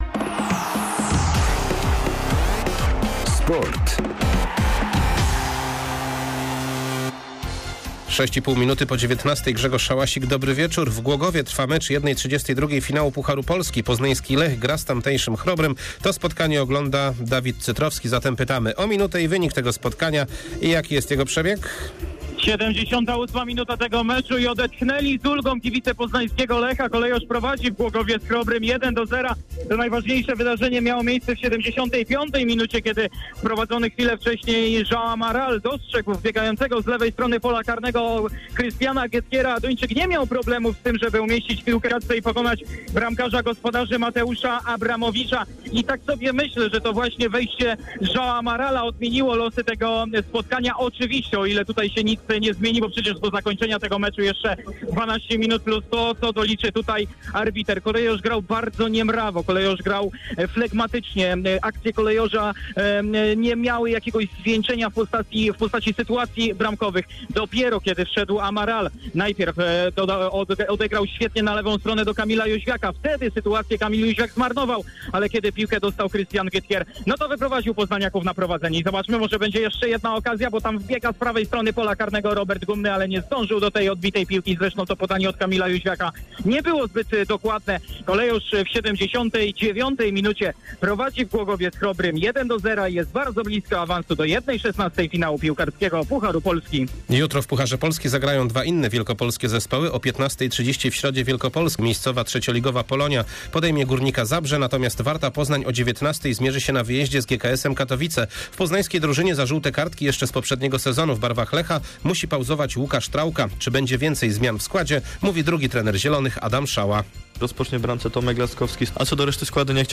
24.09. SERWIS SPORTOWY GODZ. 19:05